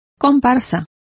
Complete with pronunciation of the translation of supernumerary.